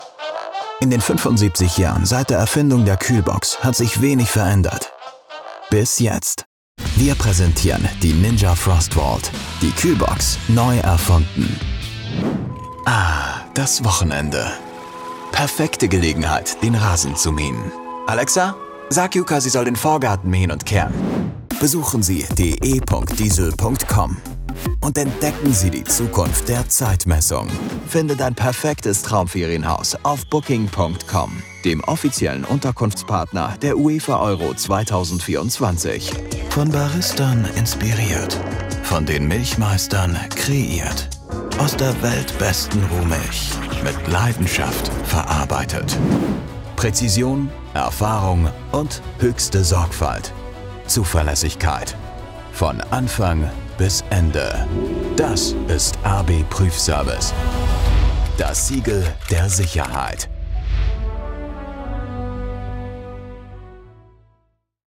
Natürlich, Zuverlässig, Freundlich, Kommerziell, Warm
Kommerziell
From his own studio, he offers high-end audio quality.